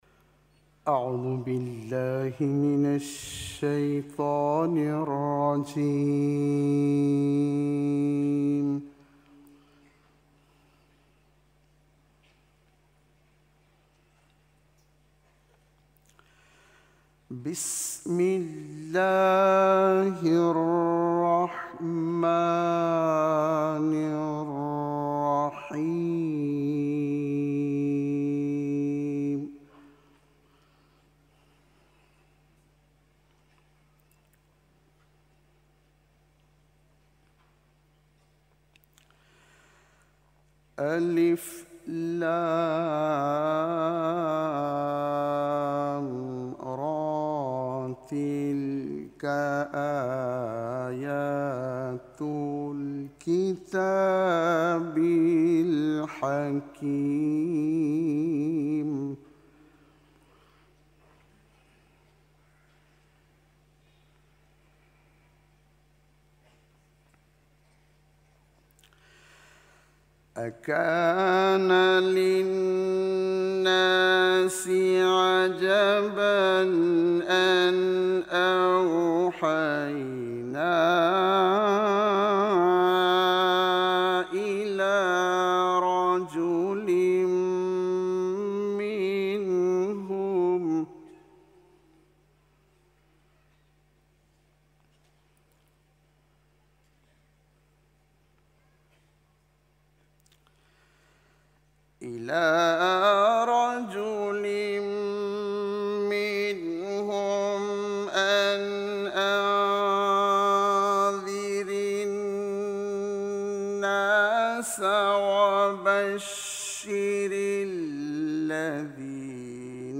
تحلیل تلاوت‌های آخرین شب مسابقات بین‌المللی قرآن مالزی + صوت